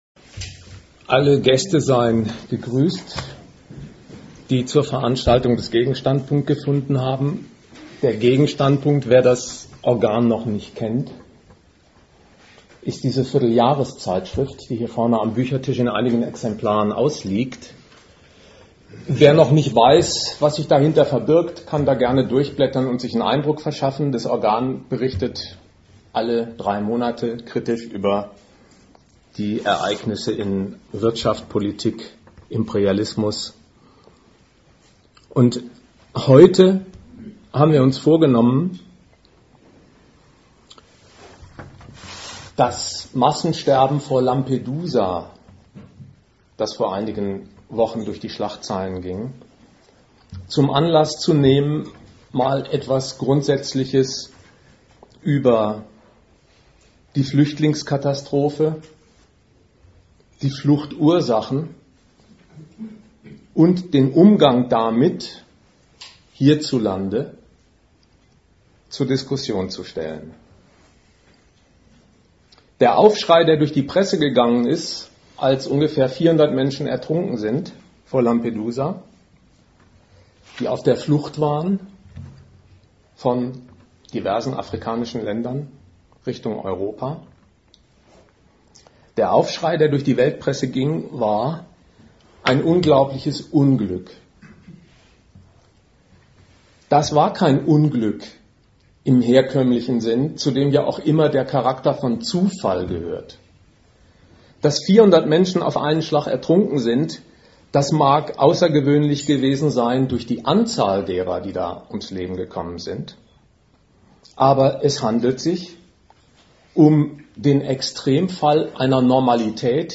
Dozent Gastreferenten der Zeitschrift GegenStandpunkt
Veranstalter: Forum Kritik Regensburg Den Vortrag bei Forum Kritik Regensburg gibt es auch hier .